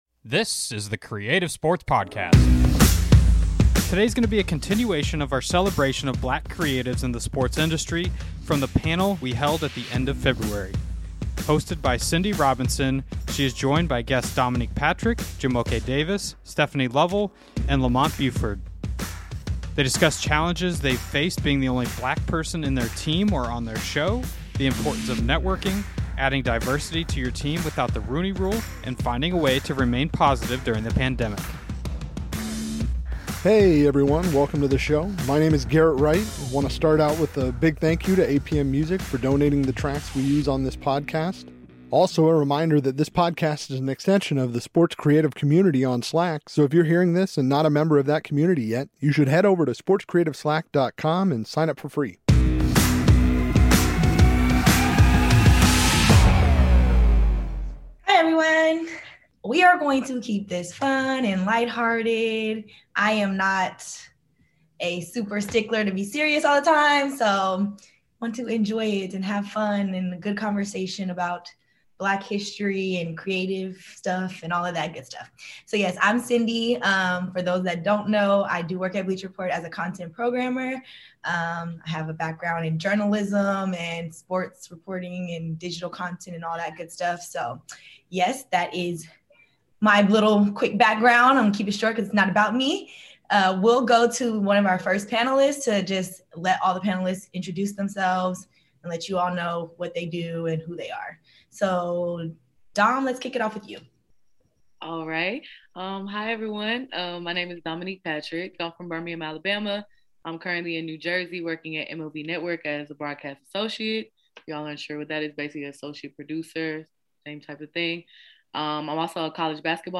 This week's podcast is a continuation of our celebration of black creatives in the sports industry from the panel we held at the end of February.